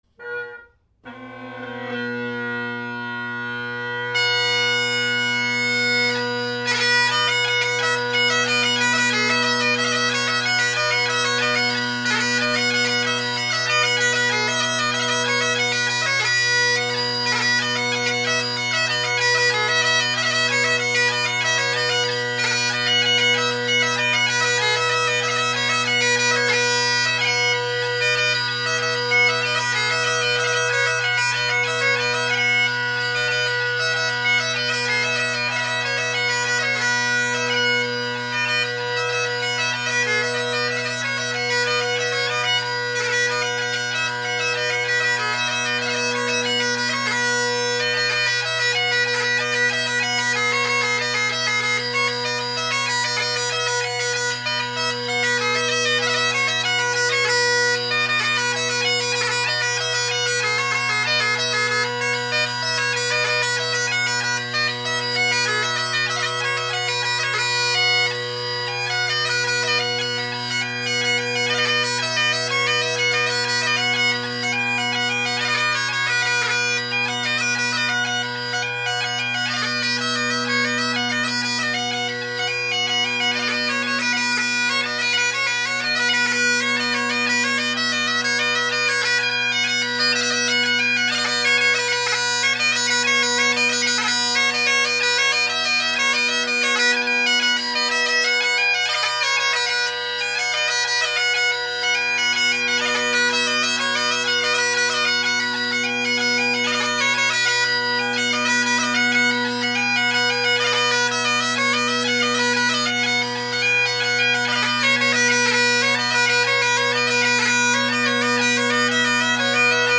Great Highland Bagpipe Solo
It was okay for light music, but they’re definitely showing their age.
Gellaitry’s with old Selbie’s: